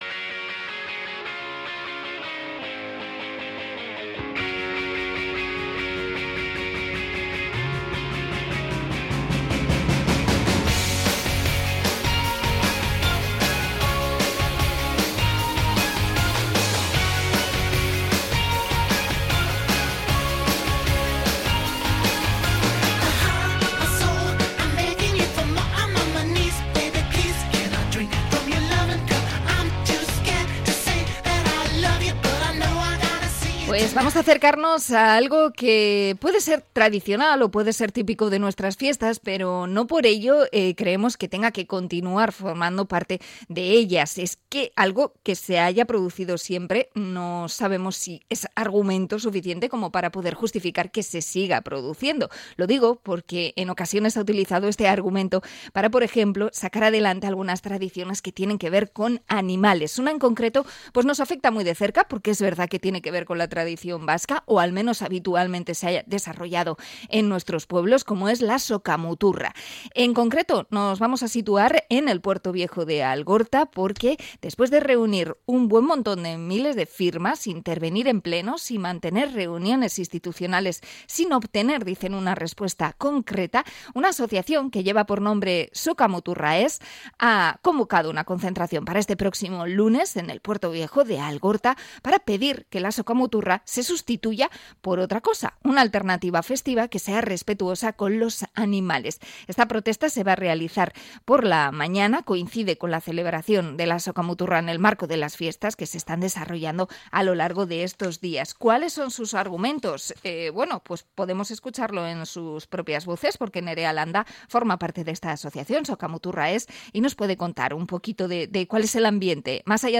Entrevista a la asociación Sokamuturra Ez